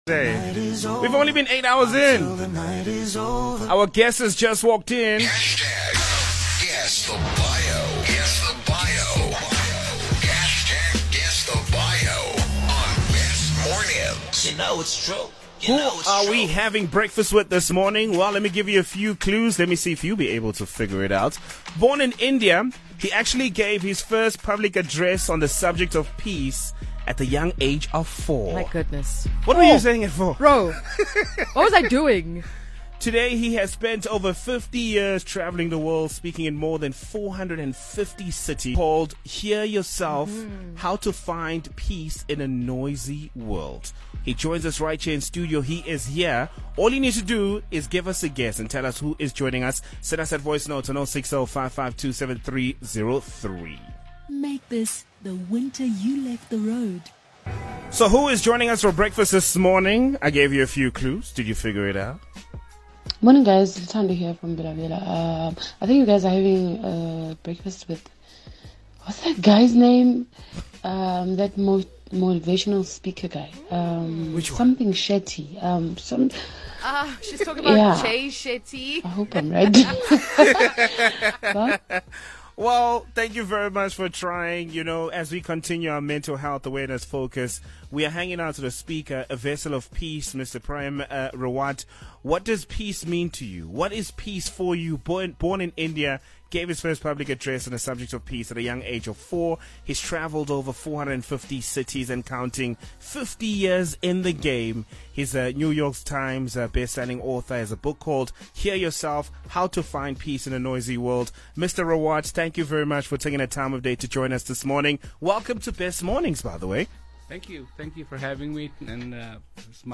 "Best Mornings" Radio Interview with Prem Rawat | Words of Peace
Listen to an interview with Prem Rawat in Cape Town, South Africa on SABC radio’s “Best Mornings” in May 2023.